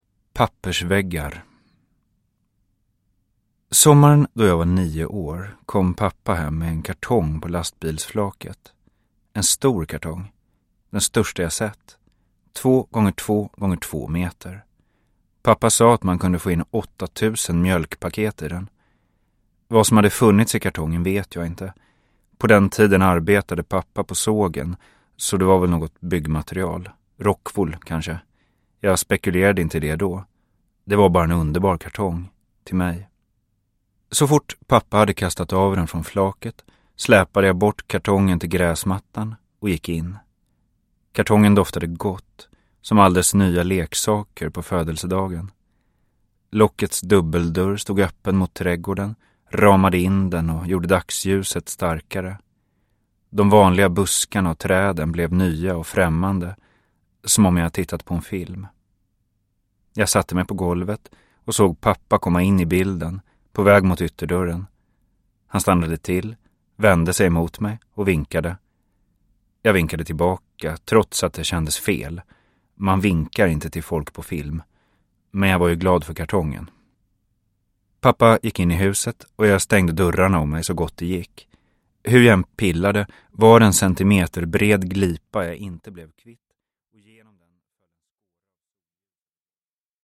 Uppläsare: John Ajvide Lindqvist